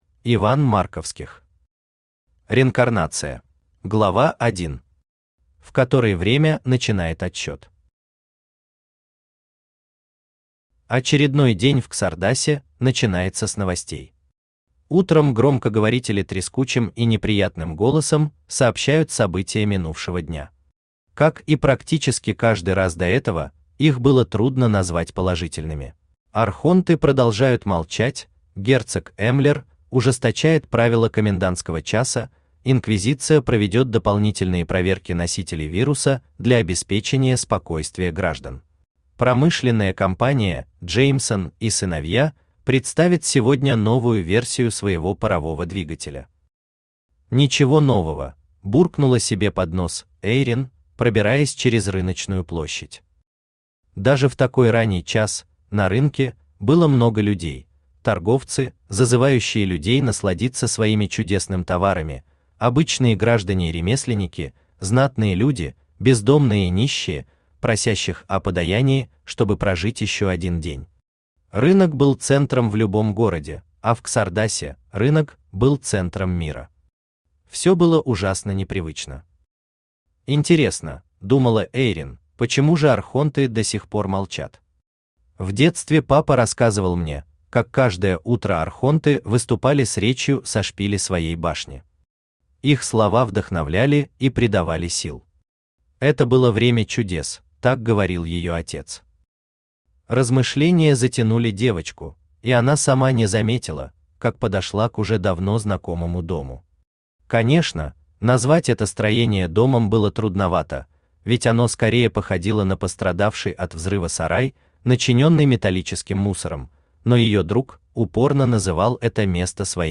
Аудиокнига Реинкарнация | Библиотека аудиокниг
Aудиокнига Реинкарнация Автор Иван Марковских Читает аудиокнигу Авточтец ЛитРес.